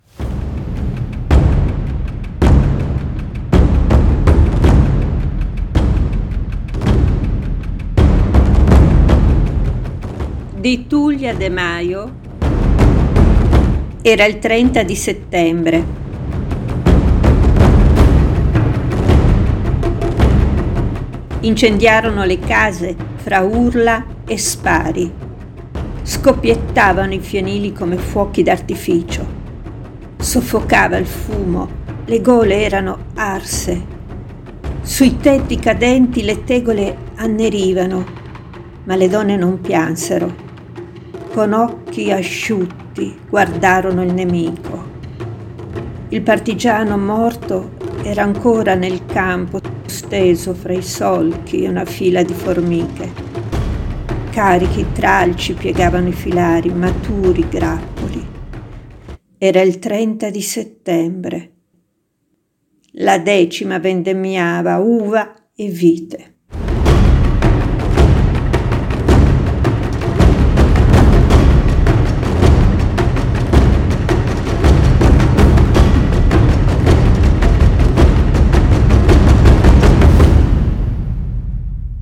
War drums